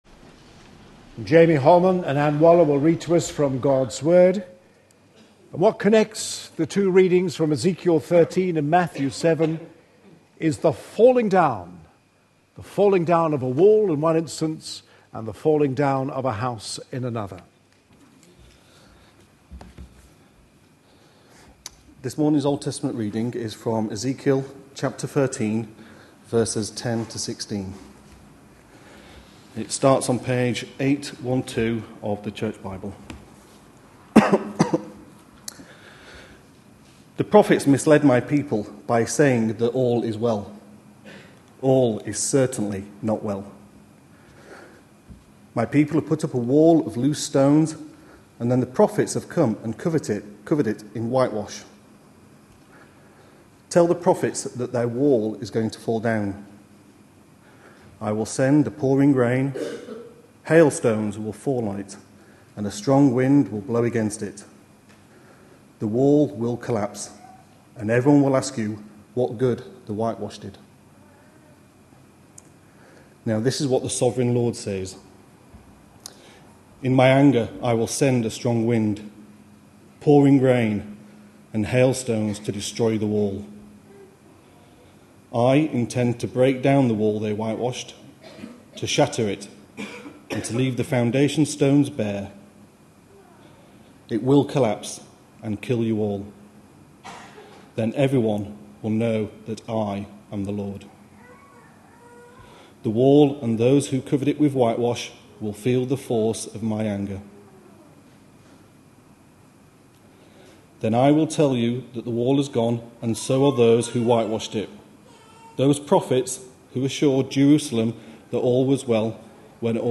A sermon preached on 29th July, 2012, as part of our Red Letter Words series.